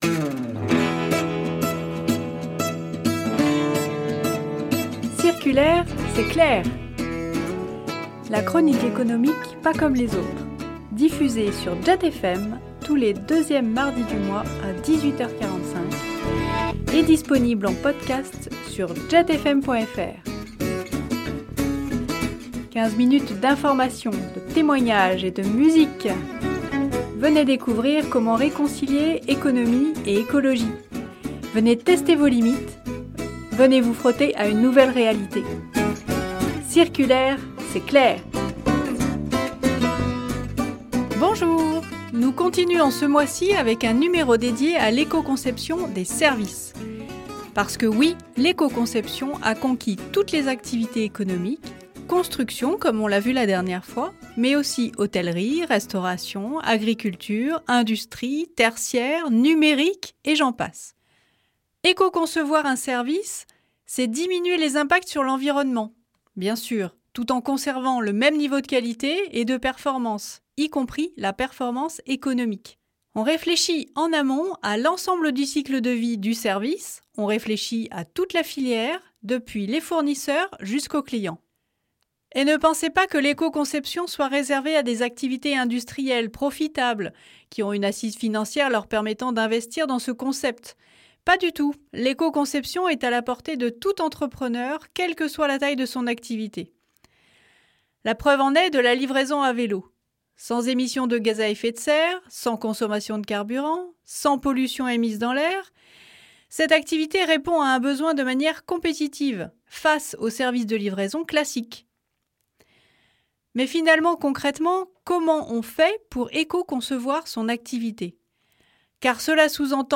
Vous allez vous régaler avec ce témoignage qui parle avec simplicité, gaieté et engagement de ce vaste sujet.